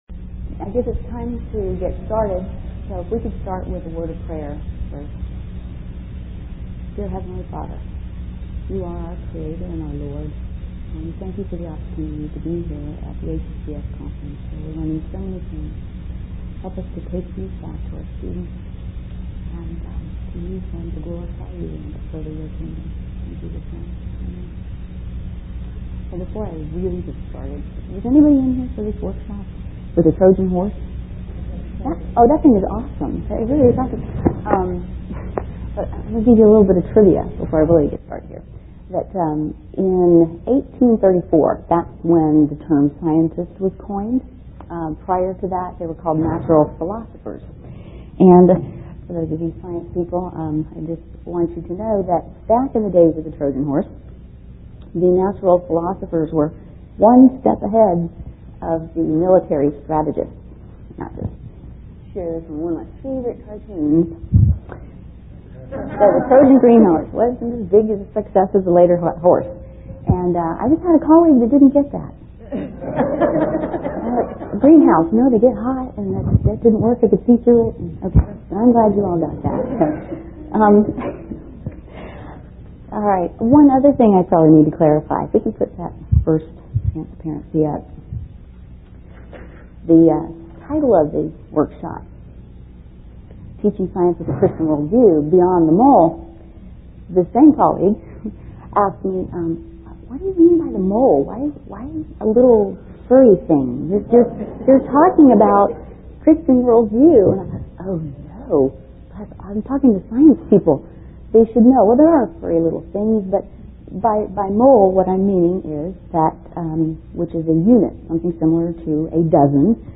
2005 Workshop Talk | 0:56:21 | All Grade Levels, Culture & Faith, Science